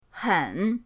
hen3.mp3